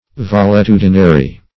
Valetudinary \Val`e*tu"di*na*ry\, a.